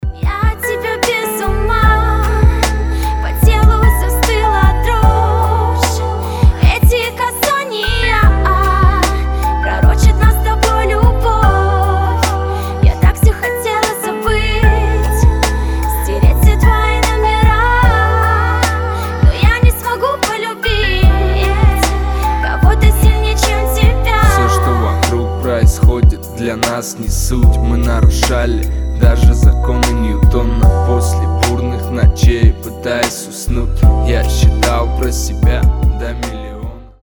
• Качество: 320, Stereo
мужской вокал
женский вокал
чувственные
лиричные